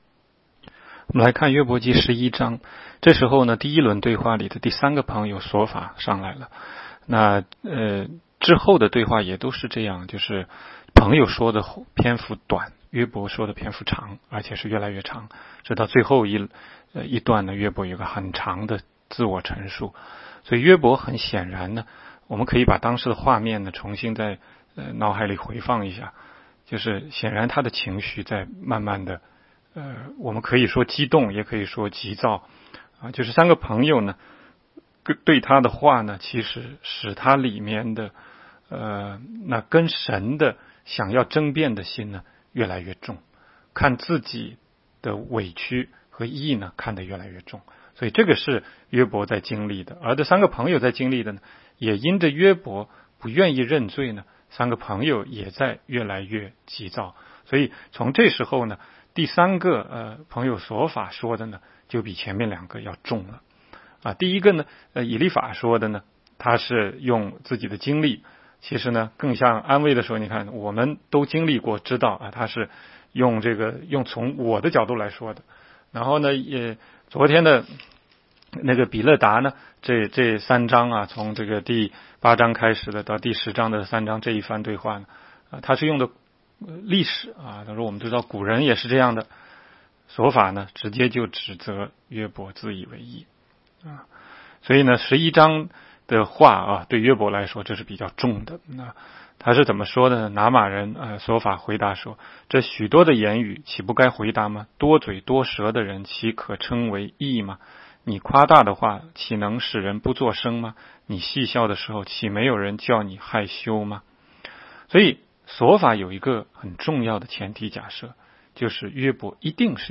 16街讲道录音 - 每日读经-《约伯记》11章